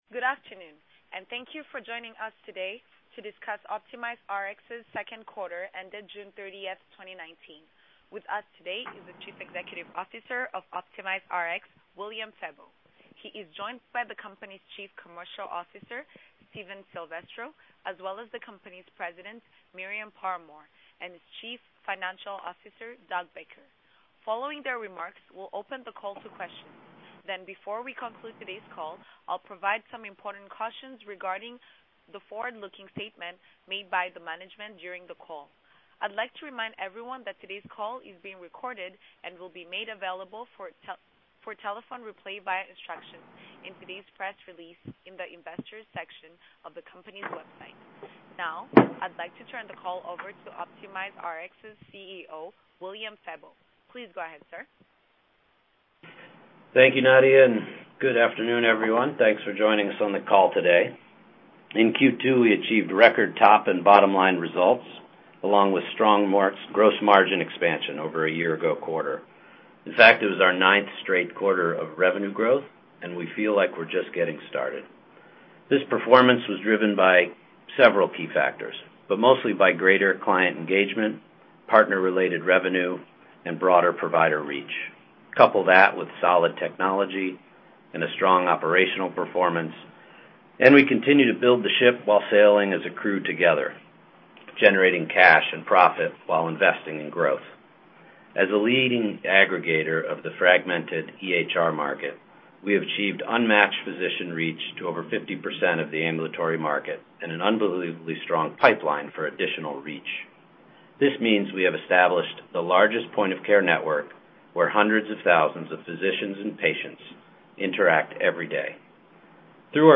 OPRX 2nd Quarter 2019 Earnings Conference Call